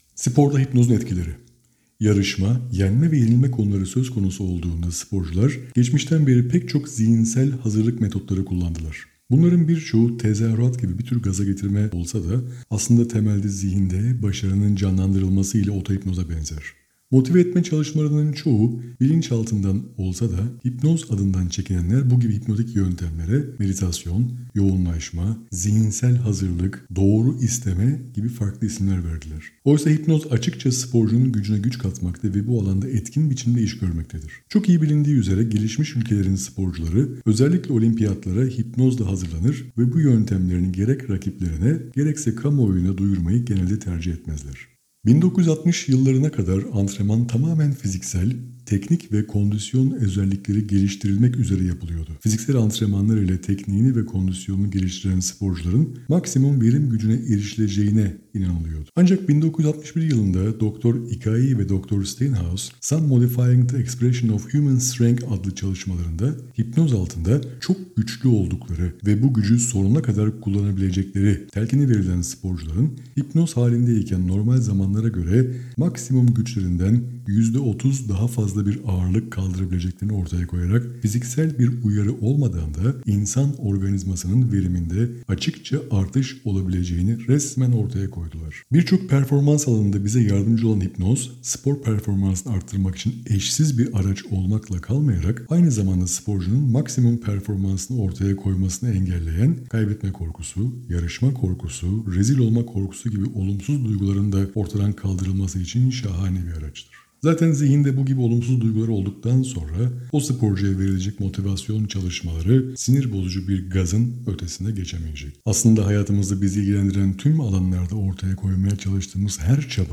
SpordaHipnozunEtkileriSesliOkuma.mp3